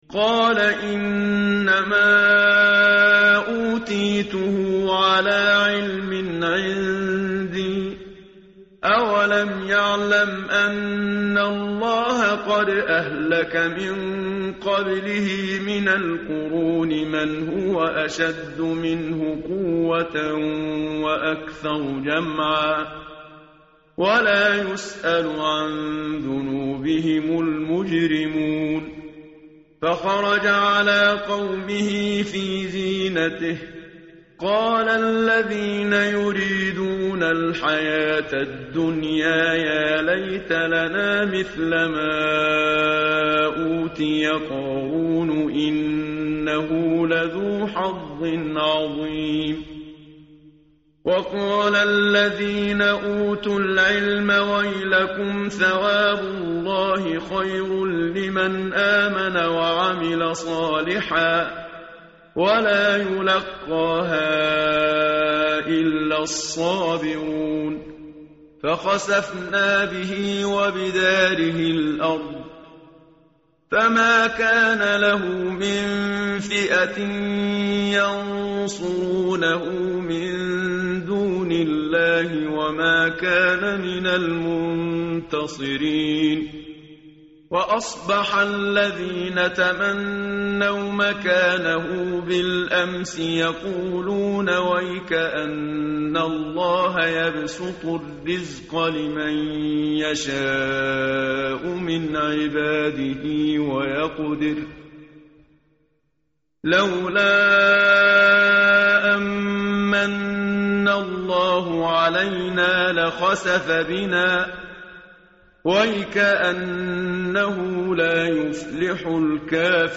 tartil_menshavi_page_395.mp3